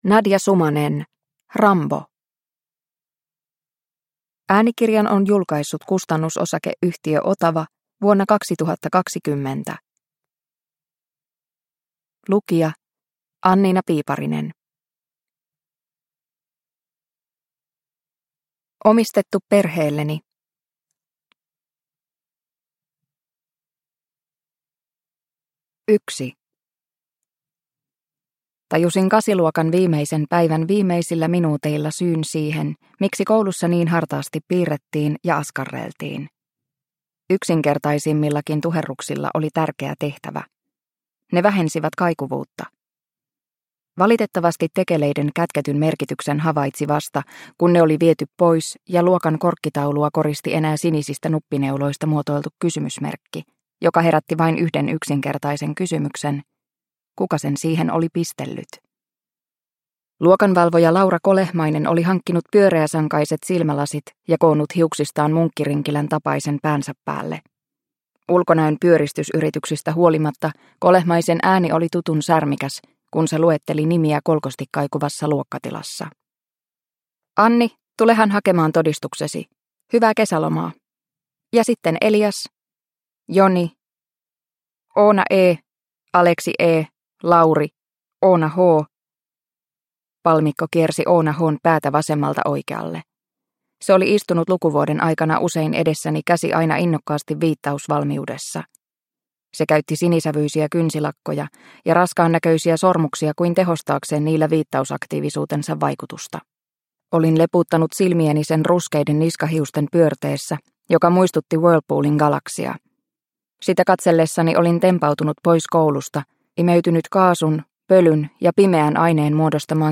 Rambo – Ljudbok – Laddas ner